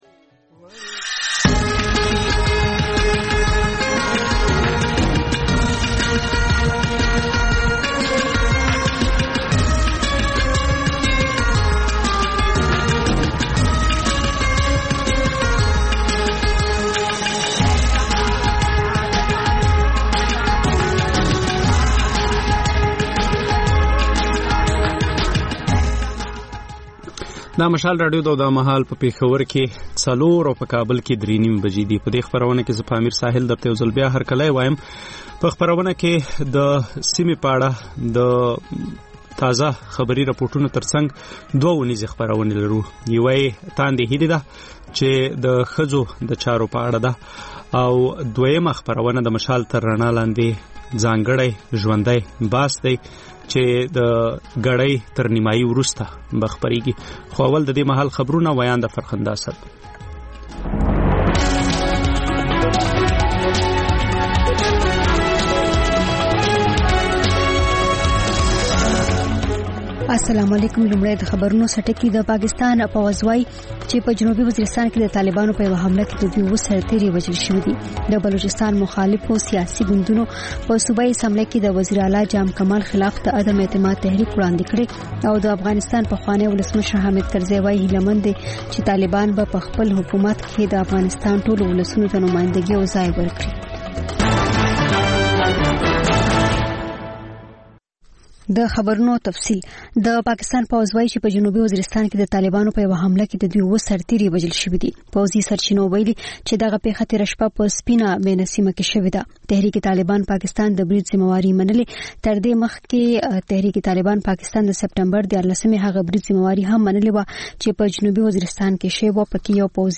د خپرونې پیل له خبرونو کېږي، بیا ورپسې رپورټونه خپرېږي. ورسره اوونیزه خپرونه/خپرونې هم خپرېږي.